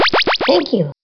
voice_thankyou.wav